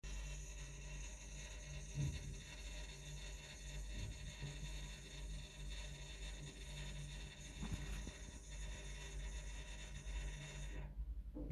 Bruit récurrent tuyauterie chambre
Le bruit s'entend dans tout l'appartement, et principalement dans la chambre où se trouve la trappe avec les canalisations.
- Un bruit de vibration fort quand mon voisin de dessous utilise de l'eau.
Cf tout l'audio "bruit 2"